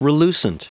Prononciation du mot relucent en anglais (fichier audio)
Prononciation du mot : relucent